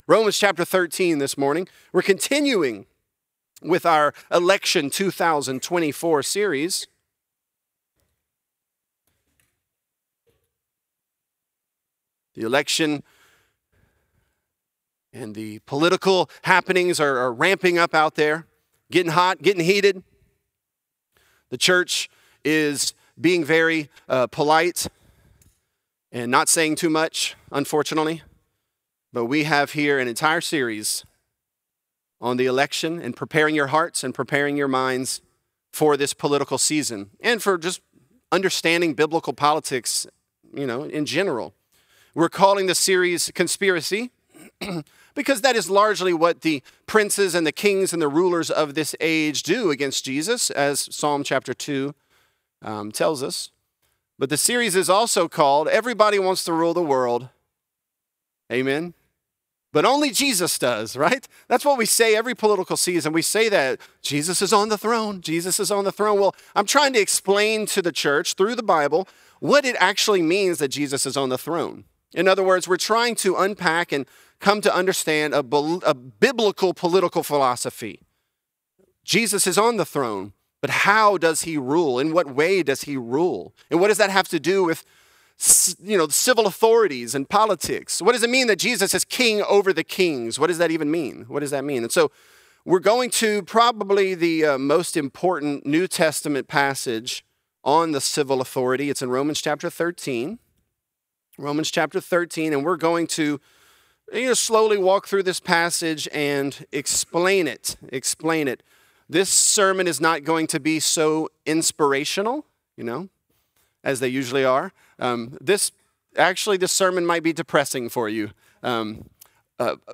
Conspiracy: One Nation Under God | Lafayette - Sermon (Romans 13)